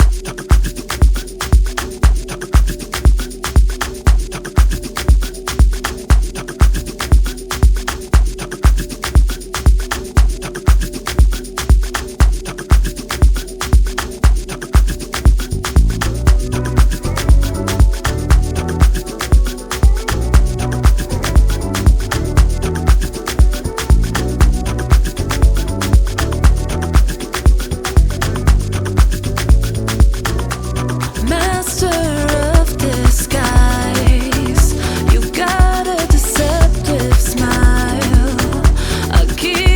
soulful vocals add depth and emotion